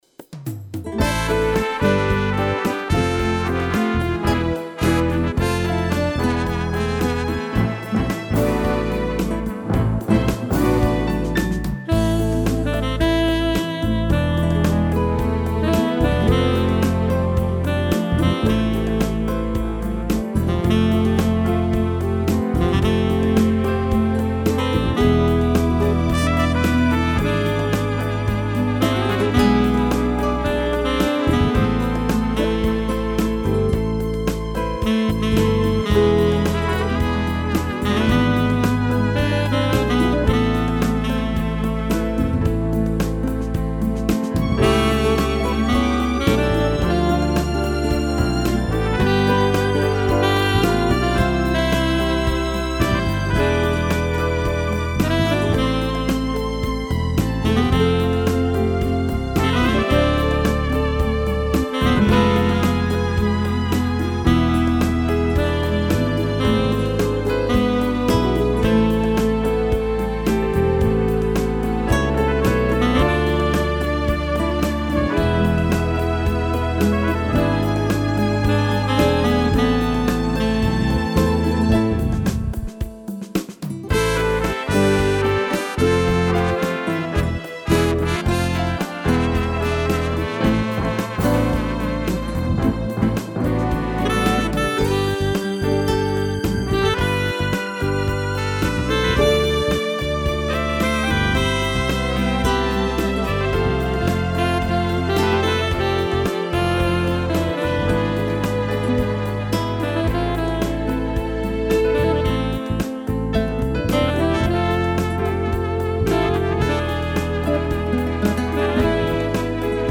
instrumental
arranjo e interpretação teclado